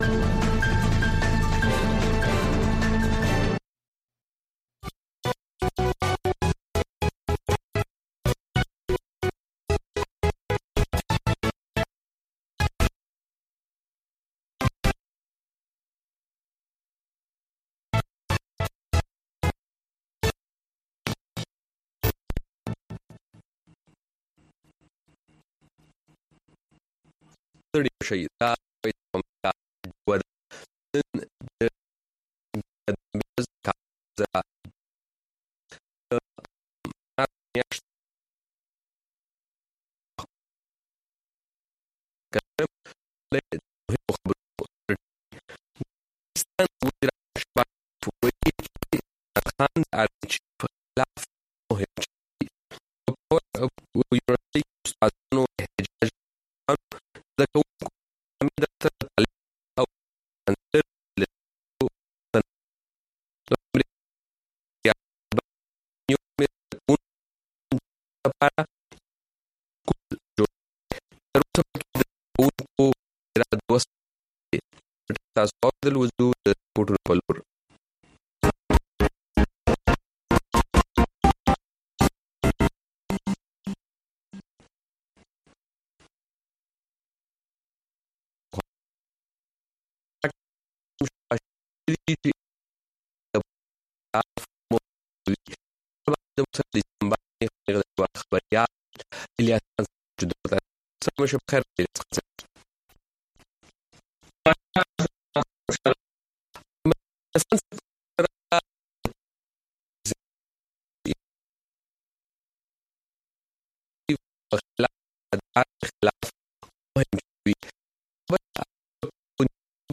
خبرونه
د وی او اې ډيوه راډيو خبرونه چالان کړئ اؤ د ورځې د مهمو تازه خبرونو سرليکونه واورئ.